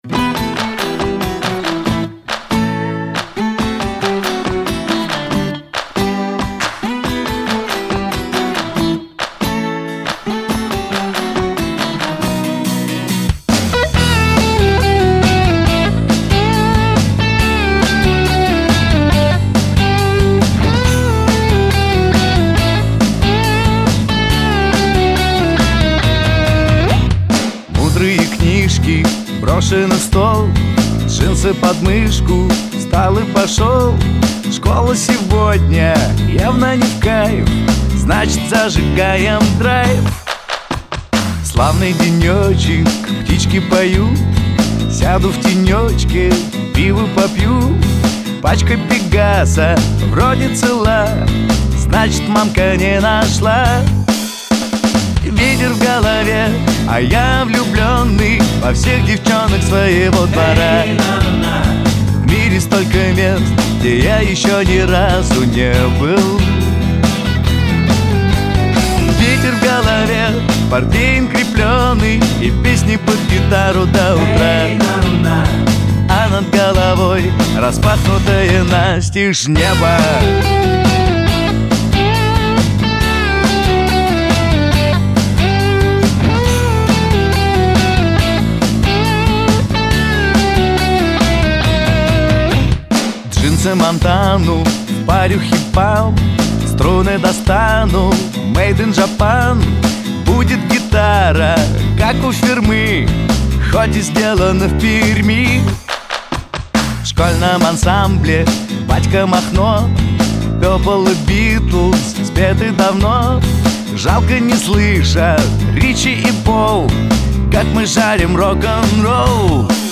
Шансон песни